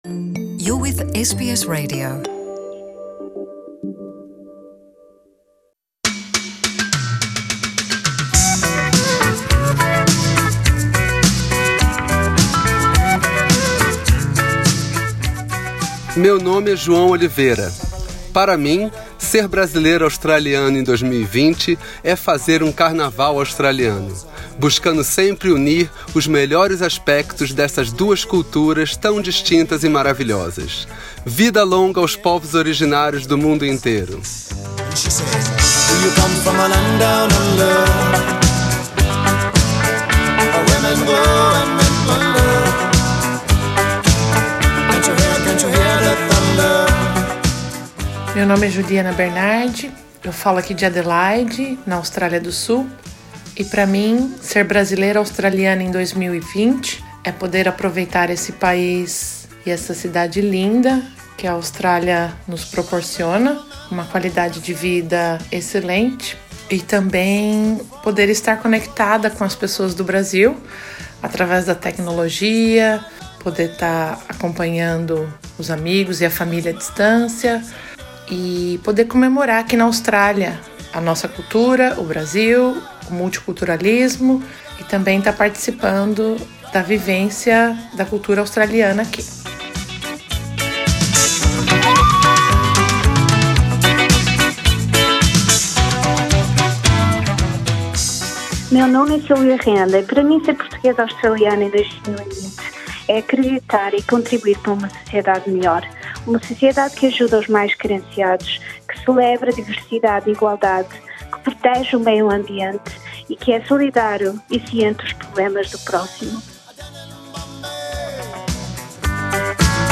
Com o Dia da Austrália celebrado no dia 26 de janeiro, perguntamos a brasileiros e portugueses como é viver influenciado pela cultura do país atual e a do país de origem. Clique no botão play acima para ouvir ao nosso Vox Pop.